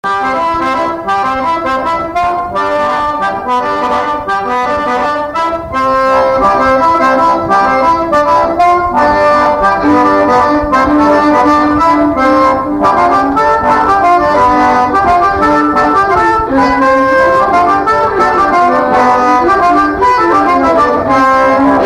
Instrumental
danse : mazurka
Pièce musicale inédite